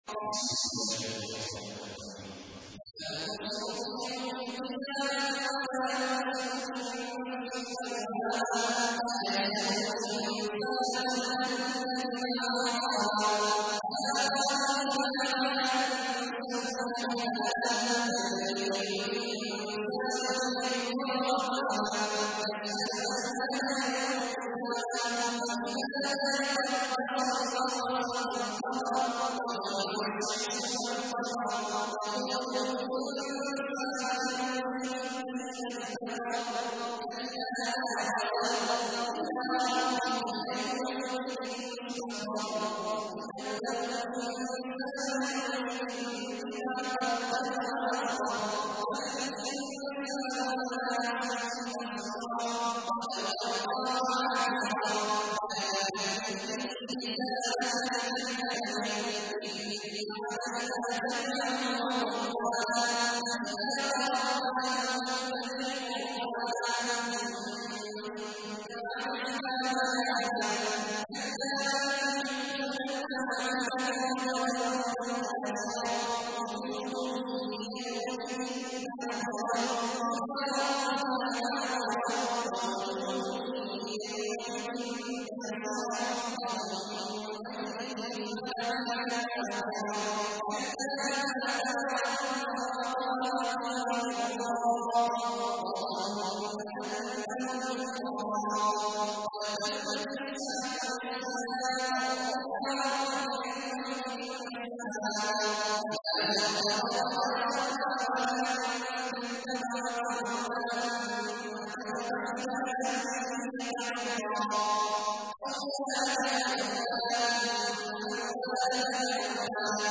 تحميل : 75. سورة القيامة / القارئ عبد الله عواد الجهني / القرآن الكريم / موقع يا حسين